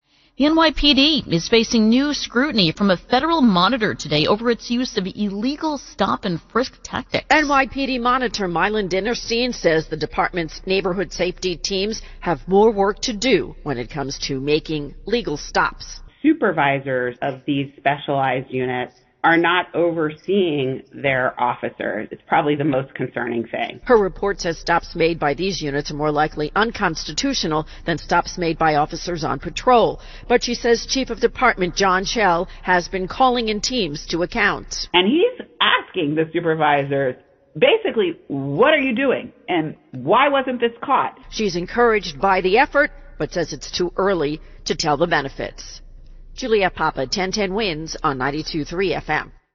Radio Segment with Monitor Interview